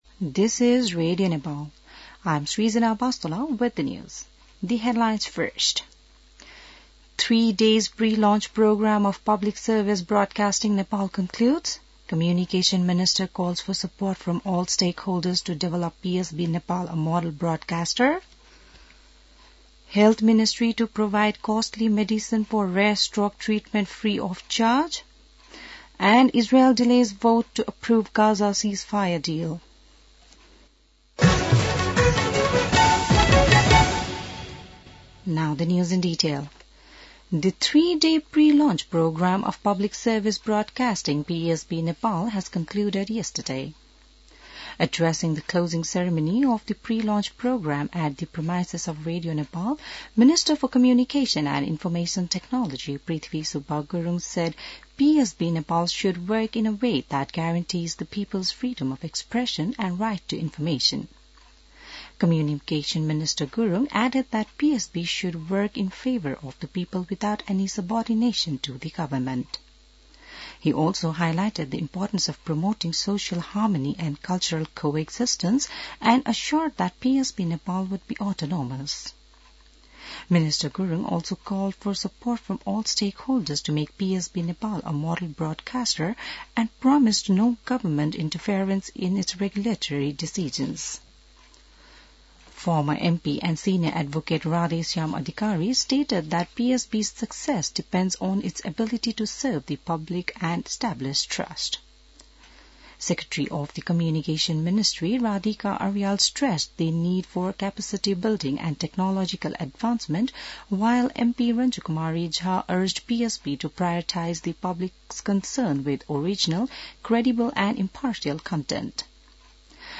बिहान ८ बजेको अङ्ग्रेजी समाचार : ५ माघ , २०८१